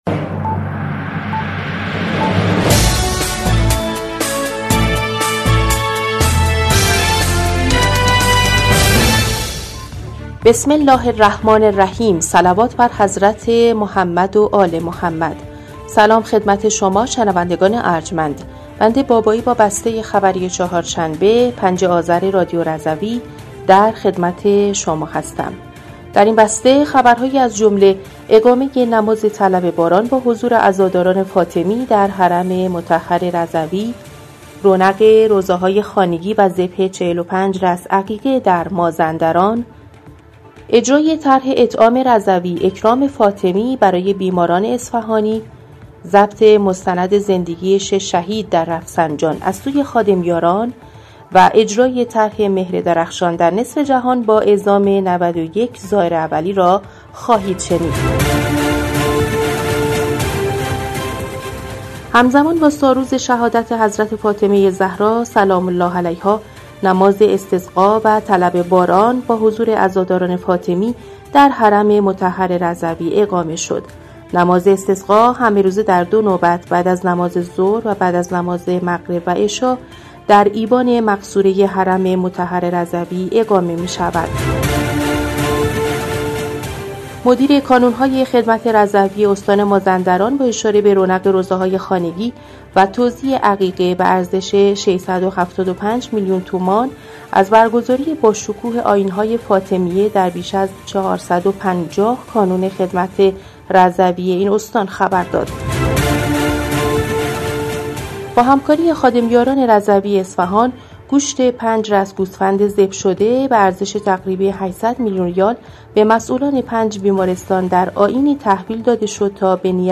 بسته خبری ۵ آذر ۱۴۰۴ رادیو رضوی؛